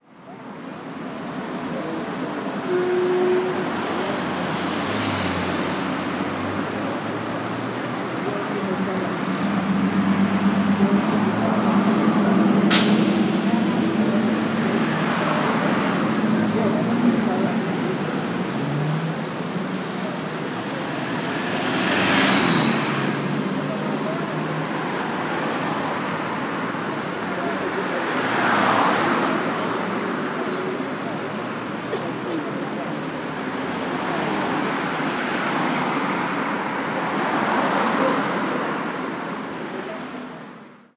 Ambiente de una calle madrileña
tráfico
tos
chirrido
freno
motor
ruido
Sonidos: Gente
Sonidos: Transportes
Sonidos: Ciudad